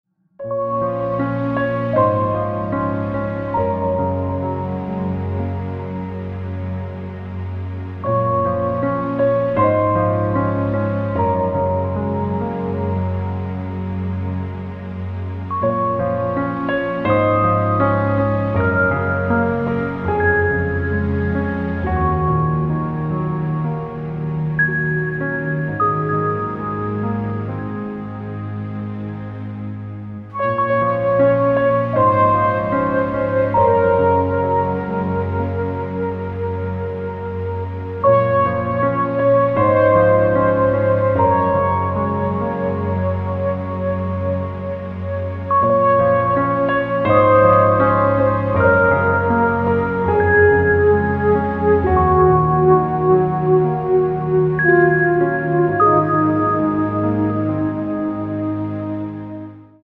• Качество: 256, Stereo
красивые
спокойные
без слов
релакс
Downtempo
инструментальные
пианино
Lounge
нежные
Chill Out